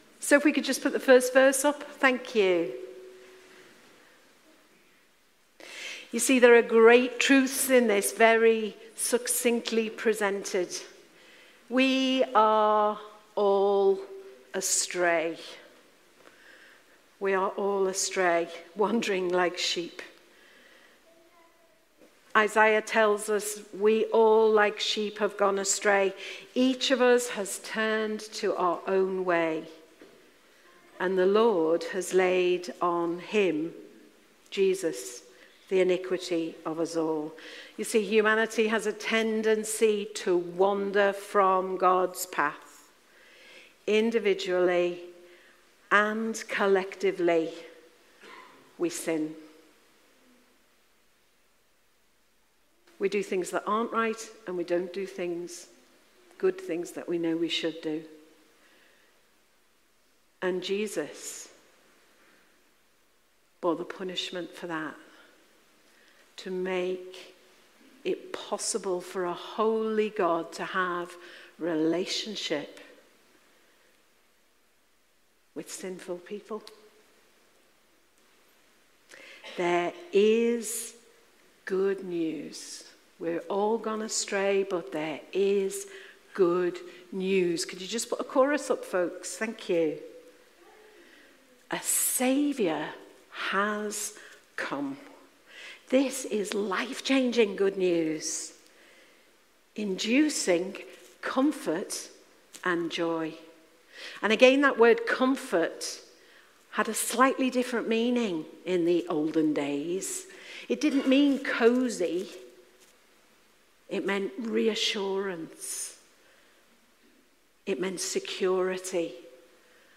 Live stream , Sounds of the Season Passage: Luke 2:8-20 Service Type: Sunday Morning « Sounds of the Season